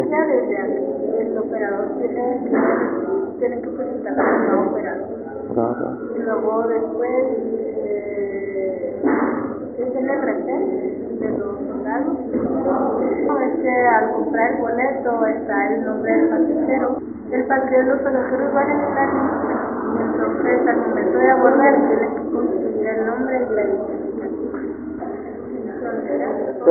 AUDIO: EMPLEADA (ANÓNIMA) DE EMPRESA DE TRANSPORTE DE PASAJEROS